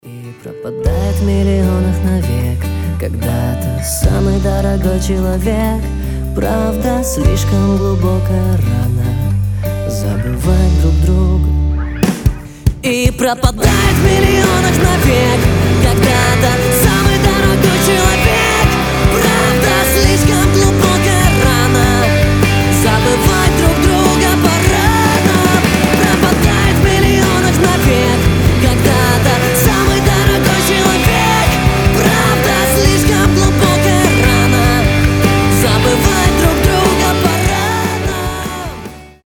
мужской вокал
грустные
нарастающие
Alternative Rock
Pop Rock